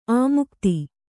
♪ āmukti